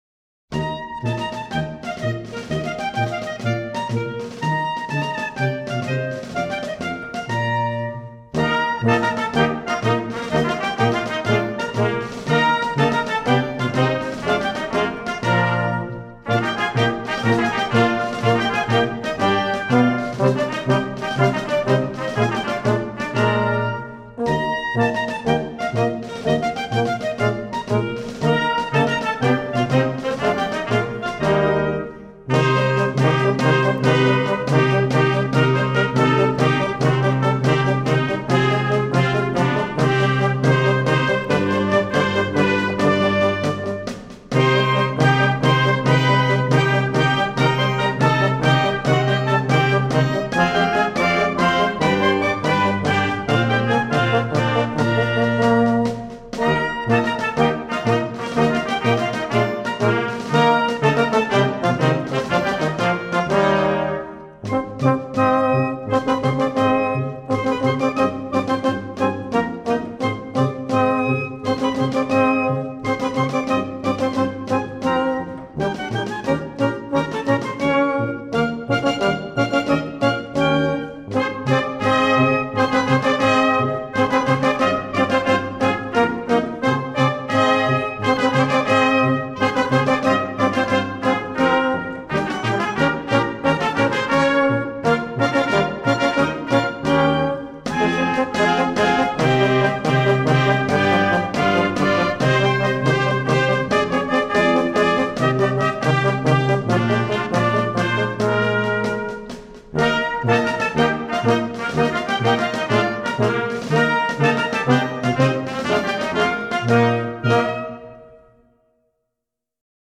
Folk Music from French-Speaking Switzerland